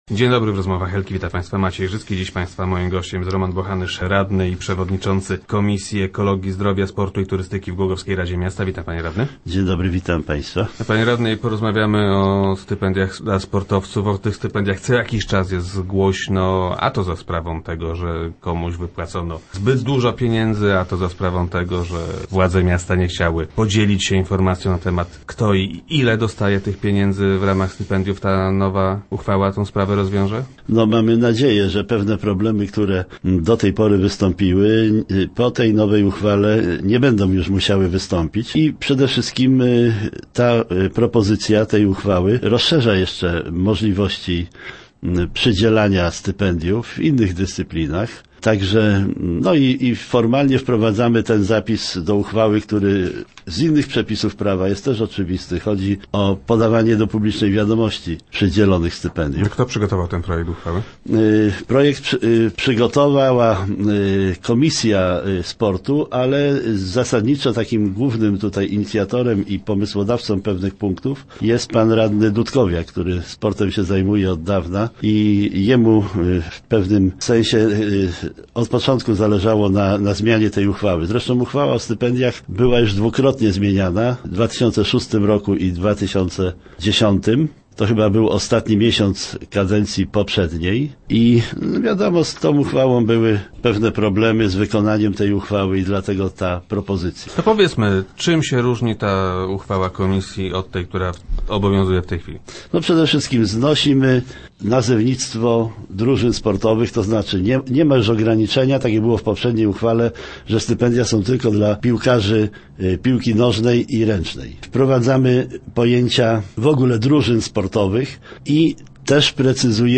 Gościem Rozmów Elki będzie radny Roman Bochanysz, przewodniczący tej komisji.